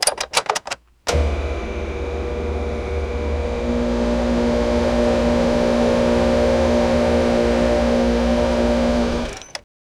keymachine-startup-o3epmdlt.wav